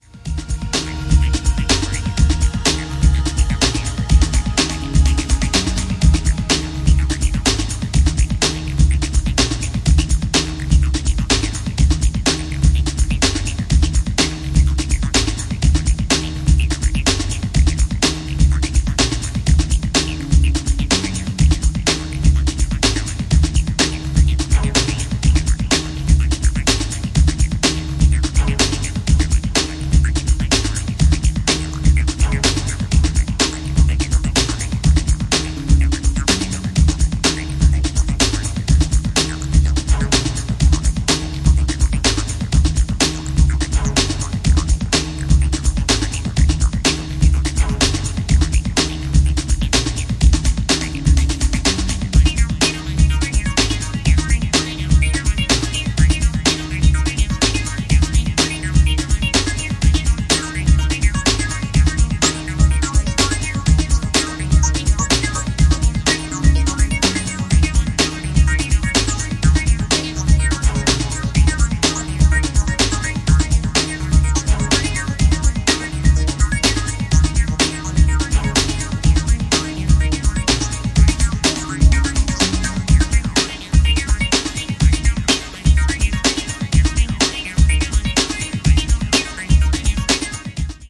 NYよりも当時のUK/EUっぽい空気感が色濃く感じられてきますね。